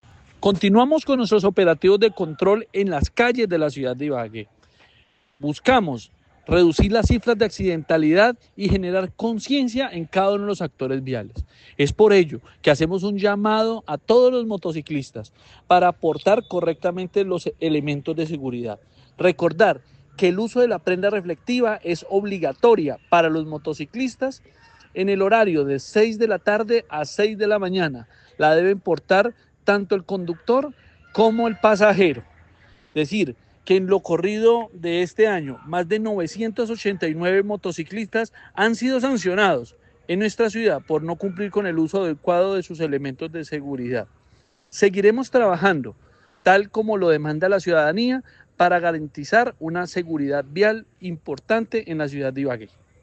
Escuche las declaraciones de Ricardo Rodríguez, secretario de Movilidad: